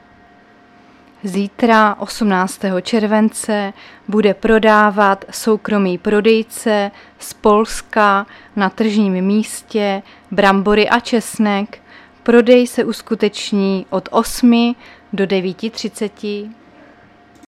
Záznam hlášení místního rozhlasu 17.7.2024
Zařazení: Rozhlas